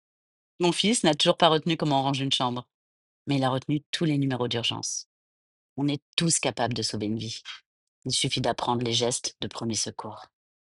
Bandes-son
Voix
25 - 50 ans